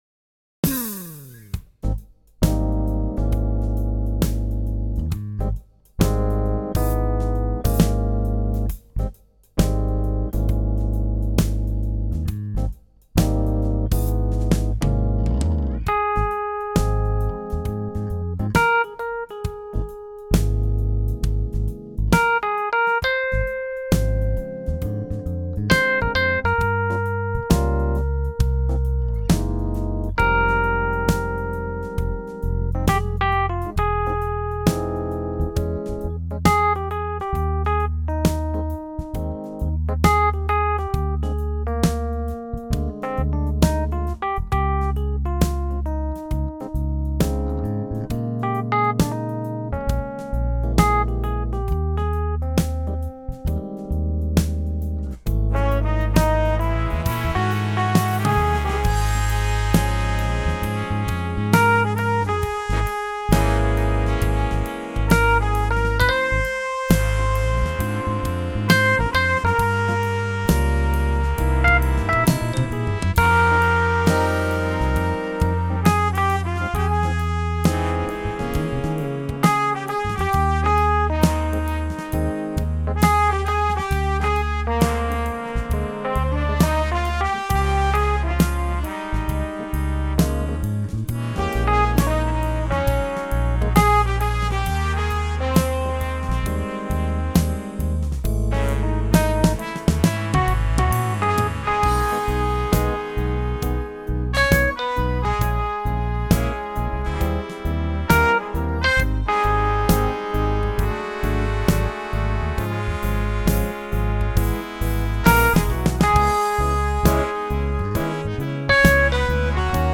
Audio Demos